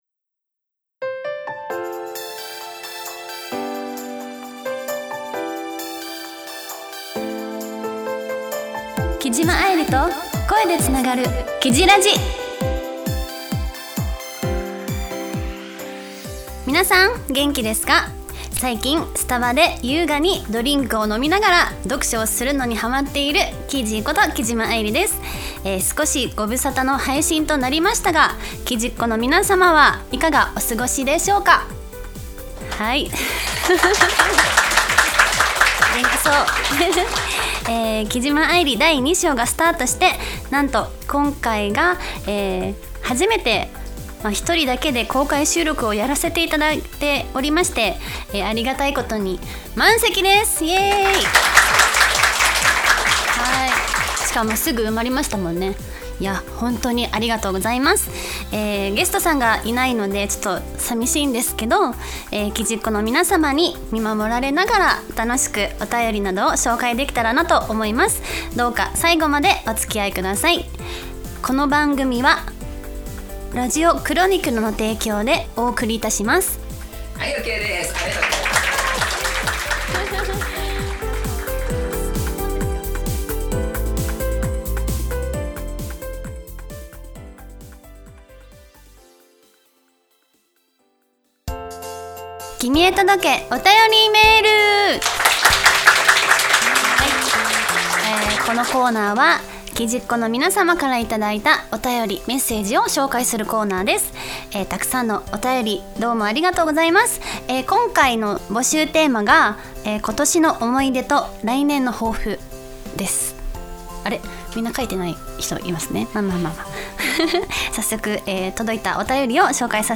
初めて一人での公開収録が開催されました！